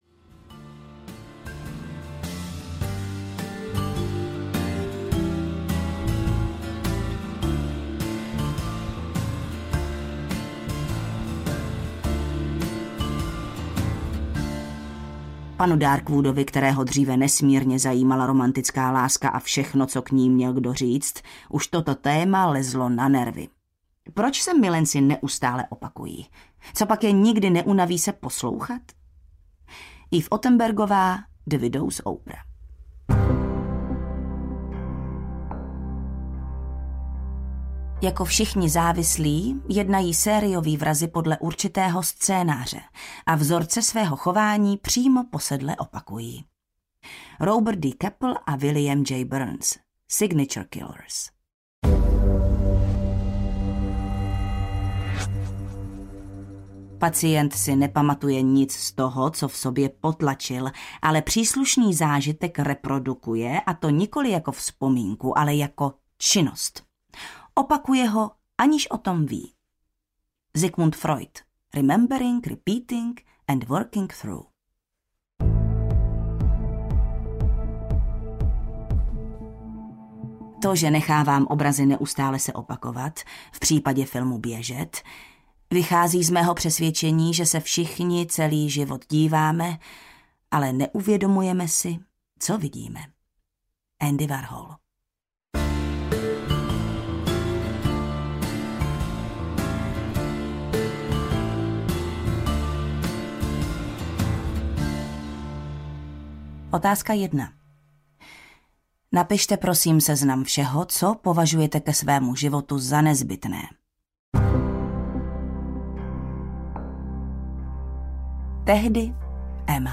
Audiokniha Ta přede mnou, kterou napsal J. P. Delaney.
Ukázka z knihy